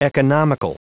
Prononciation du mot economical en anglais (fichier audio)
Prononciation du mot : economical